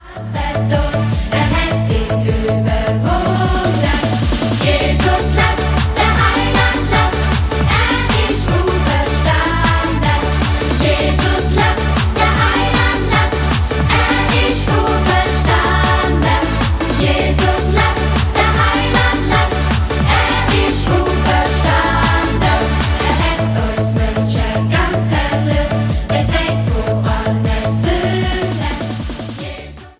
neue und alte Dialektsongs für Kinder